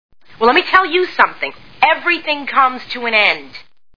The Sopranos TV Show Sound Bites